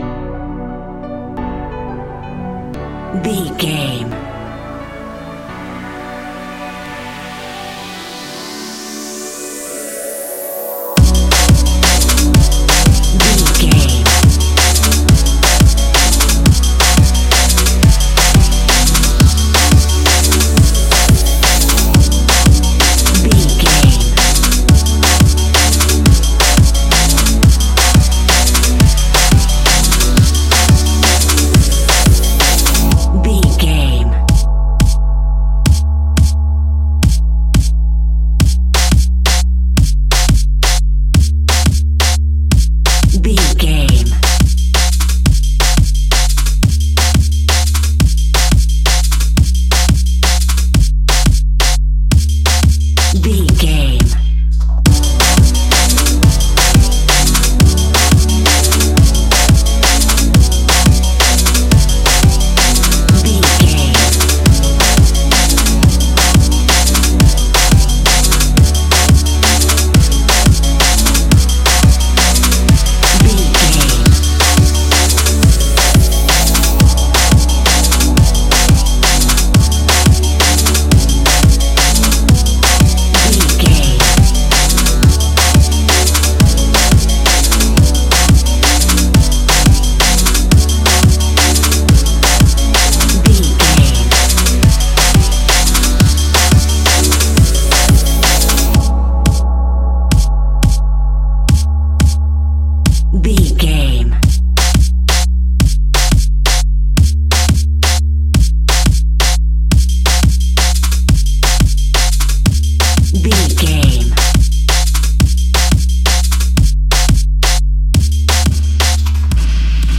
Ionian/Major
electronic
techno
trance
synthesizer